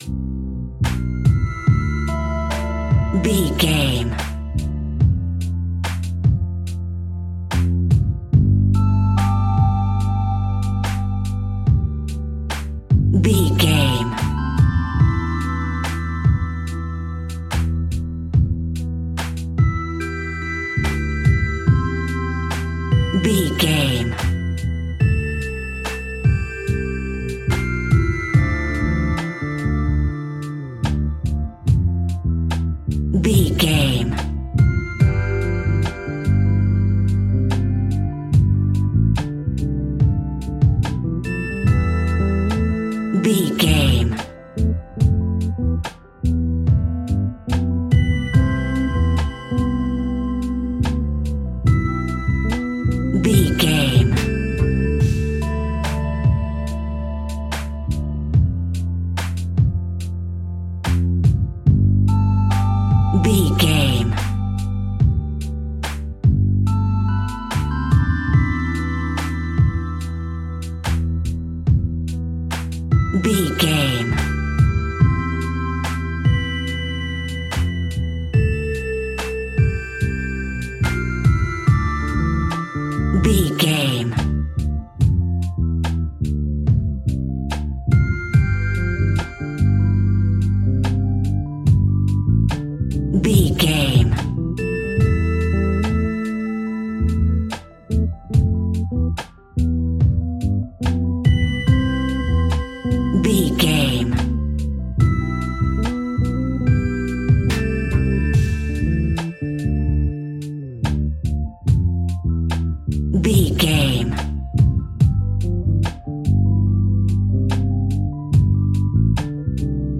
Ionian/Major
chilled
laid back
Lounge
sparse
new age
chilled electronica
ambient
atmospheric
instrumentals